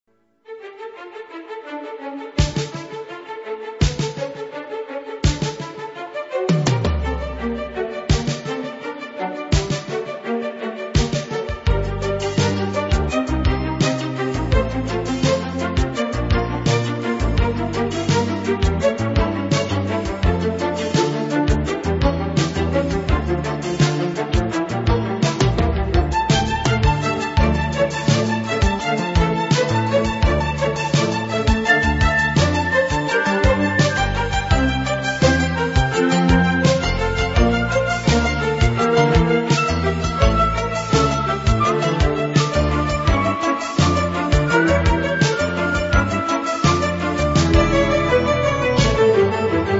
в современной обработке